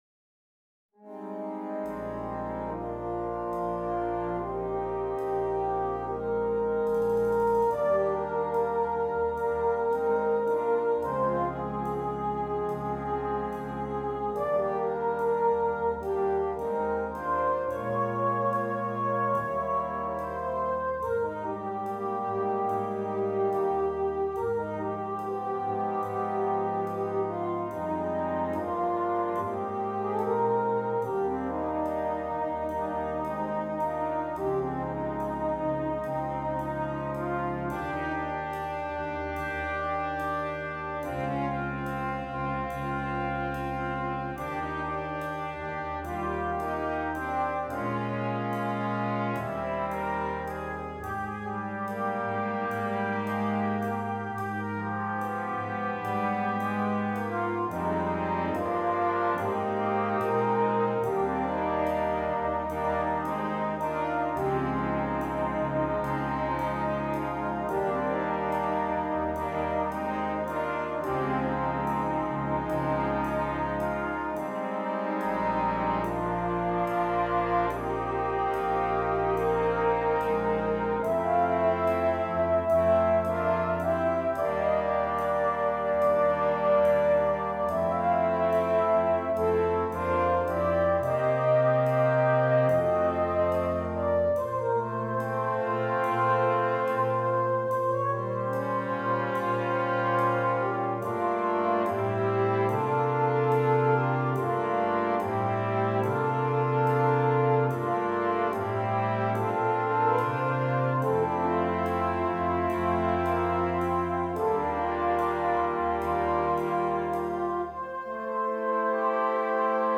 Brass Quintet (optional Drum Set)
rich harmonies